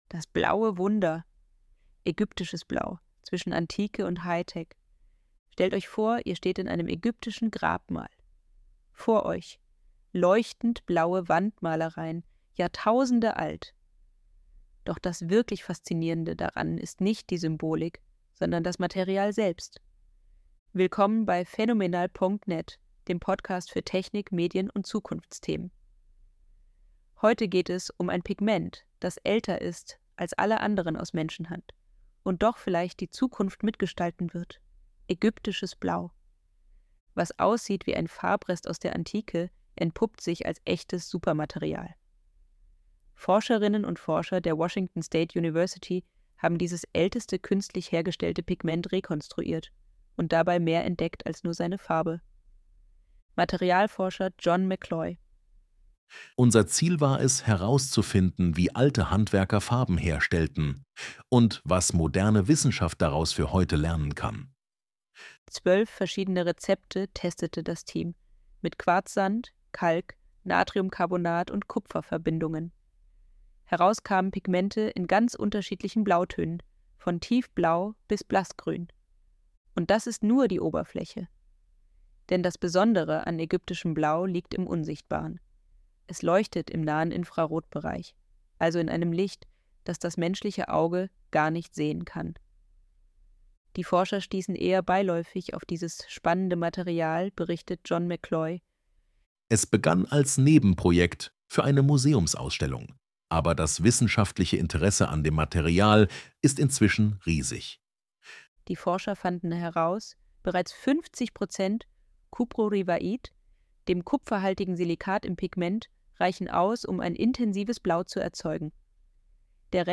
ElevenLabs_Probepodcast_Nr._2.mp3